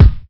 • 2000s Bassy Kick Single Hit A# Key 52.wav
Royality free kick single shot tuned to the A# note. Loudest frequency: 151Hz